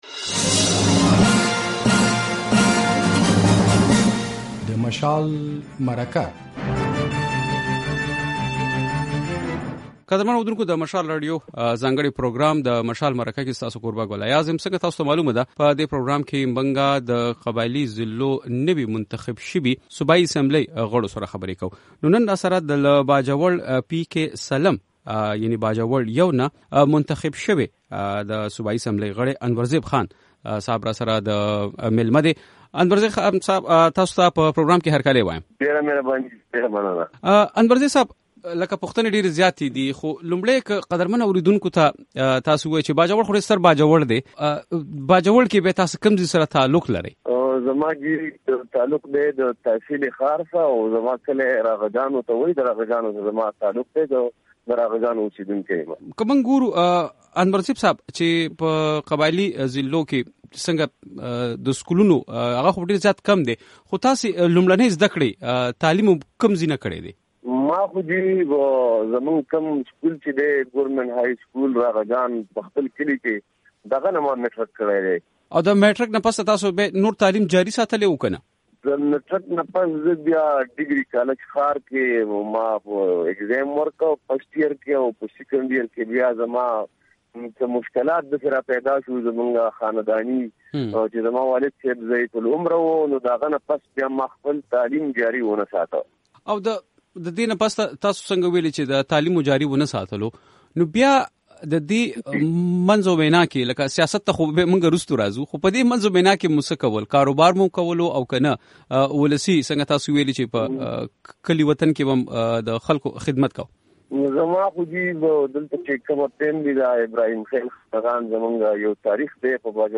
له باجوړه نوي منتخب شوي د صوبايي اسمبلۍ غړي انور زيب سره مرکه
له انور زيب سره د مشال مرکه دلته واورئ